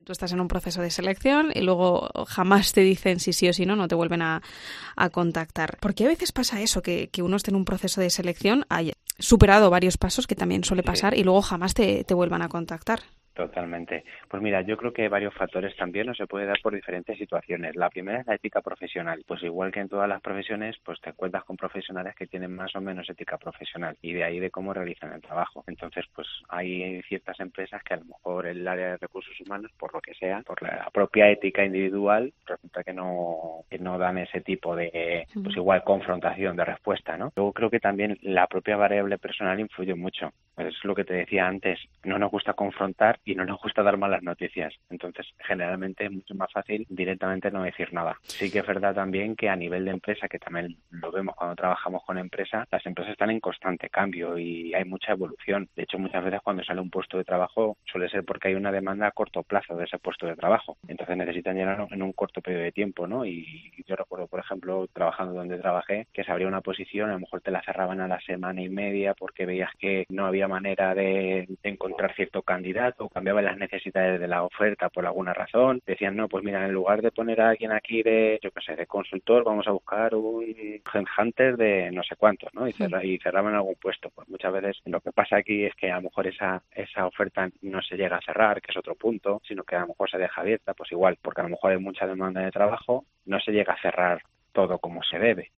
psicólogo experto en RRHH